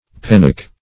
penock - definition of penock - synonyms, pronunciation, spelling from Free Dictionary Search Result for " penock" : The Collaborative International Dictionary of English v.0.48: Penock \Pen"ock\, n. See Pend .